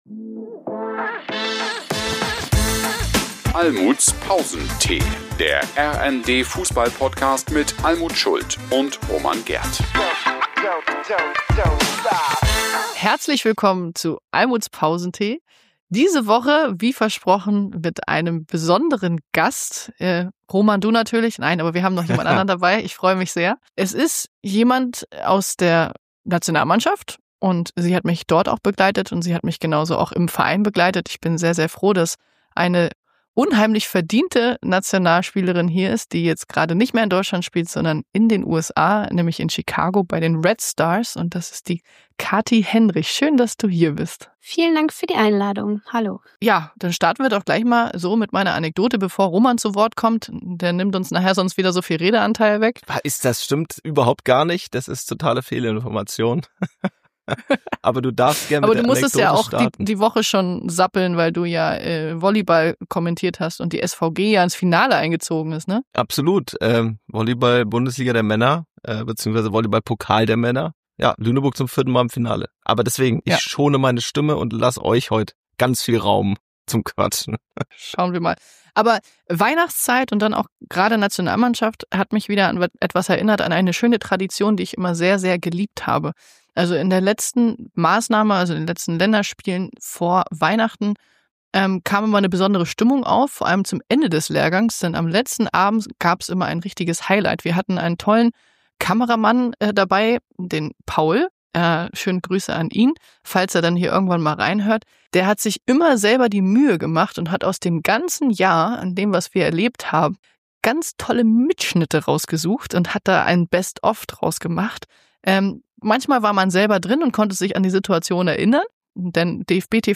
Folge 69: Noch einmal WM? – Nationalspielerin KATHY HENDRICH im Interview (Teil 1) ~ Fußball Podcast
folge-69-noch-einmal-wm-nationalspielerin-kathy-hendrich-im-interview-teil-1.mp3